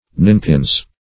Ninepins \Nine"pins\, n. pl.